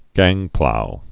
(găngplou)